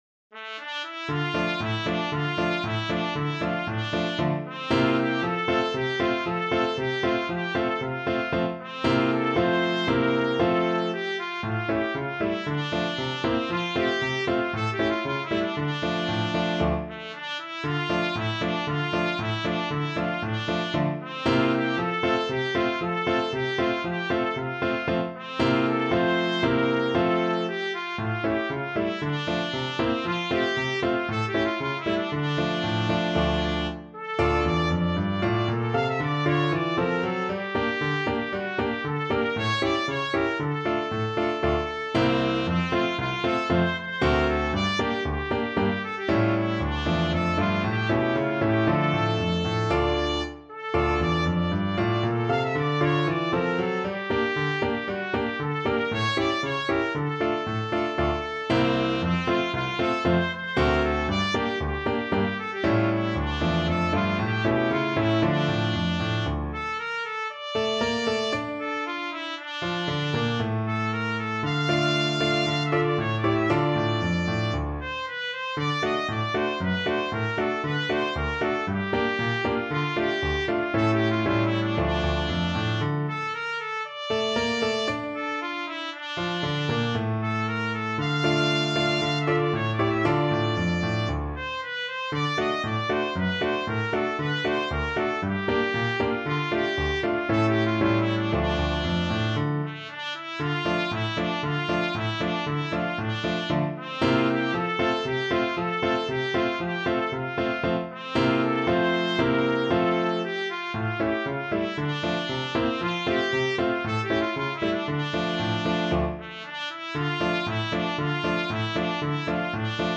Allegro =c.116 (View more music marked Allegro)
2/4 (View more 2/4 Music)
Traditional (View more Traditional Trumpet Music)